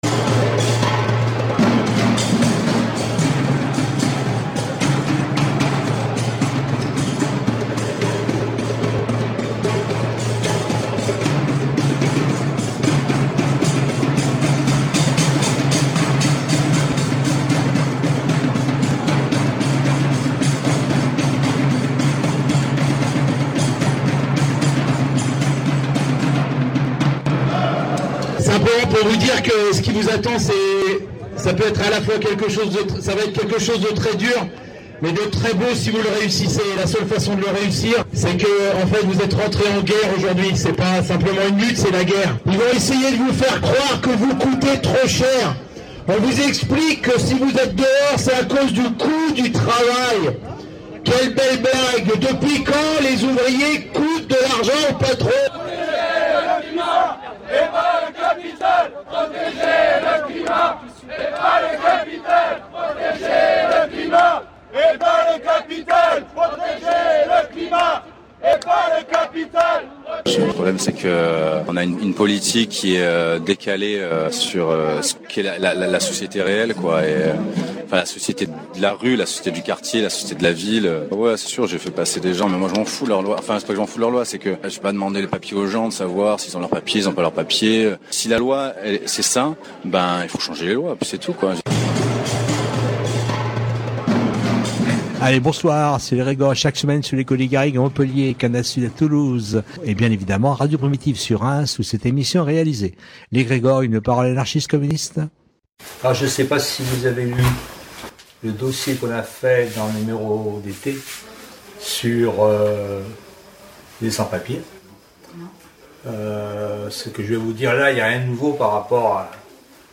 Ce soir, nous diffusons la 1ère partie du débat qui a eu lieu cet été aux Rencontres Libertaires du Quercy concernant l’urgence de la solidarité à amener aux immigré-e-s. Dans la plupart des cas, l’administration française ne répond même plus aux demandes de régularisation des sans-papiers.